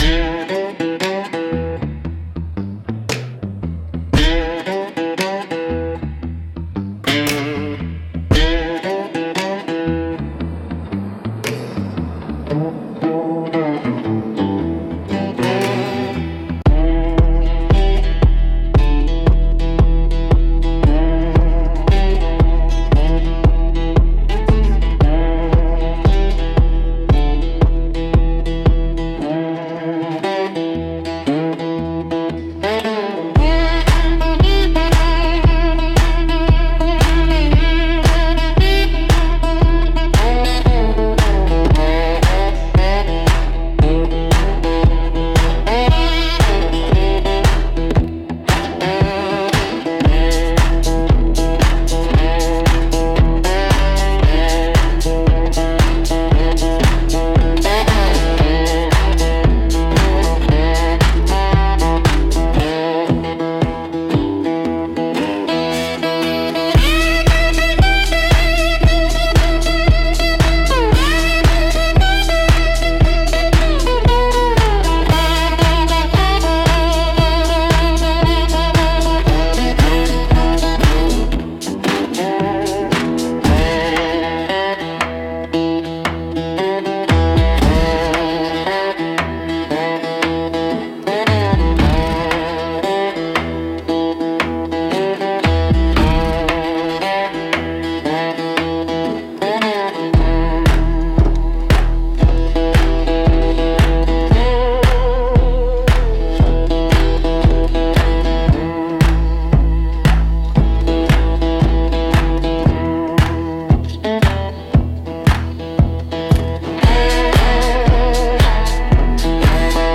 Instrumental -Violin of Ashes - 3.37